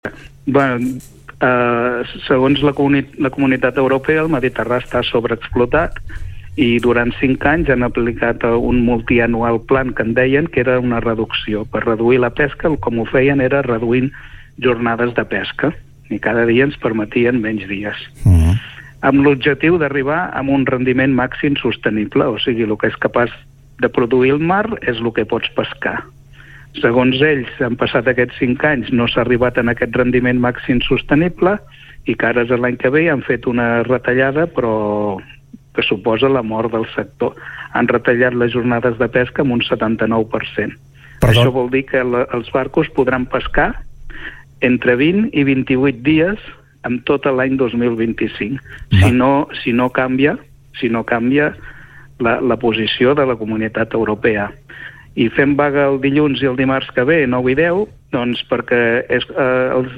En una entrevista a TARDA Capital